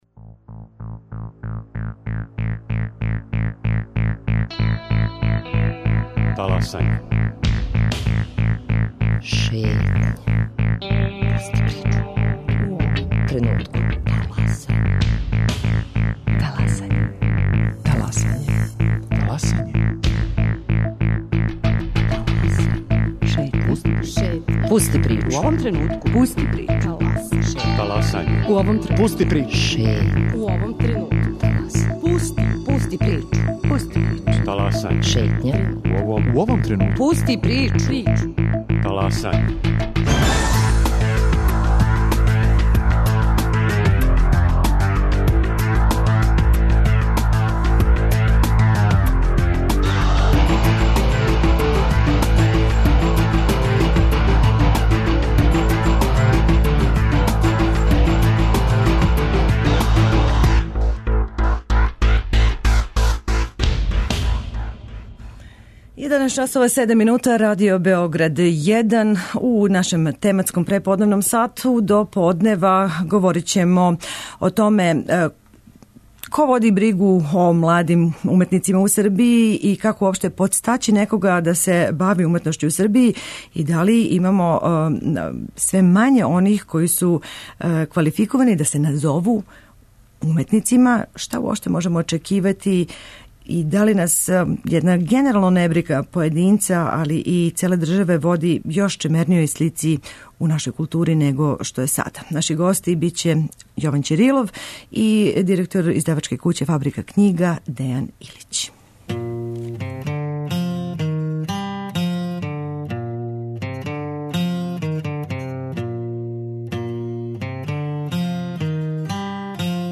У тематском сату бавићемо се положајем младих уметника у Србији. Као повод смо узели један од све успешнијих конкурса за сликаре и графичаре до 35 година који већ седам година организује 'Ниш арт фондација' коју у Београду представља један од њених оснивача Јован Ћирилов, који је наш гост.